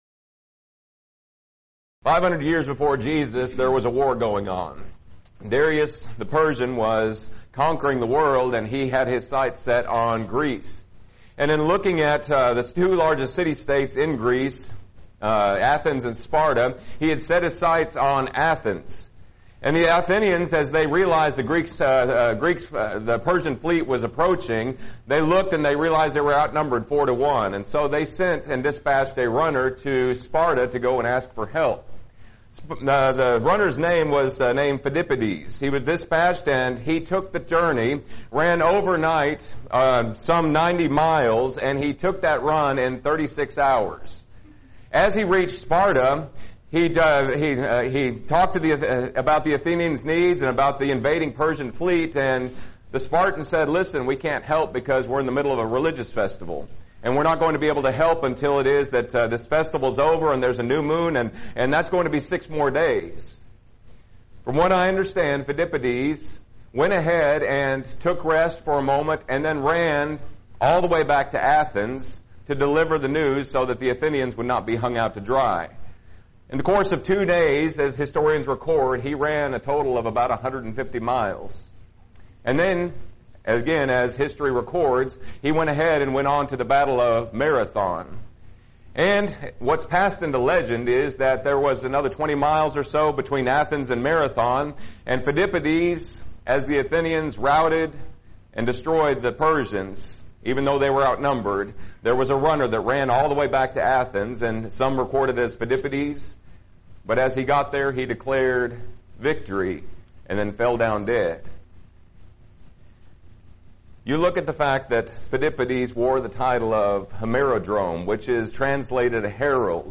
Song Study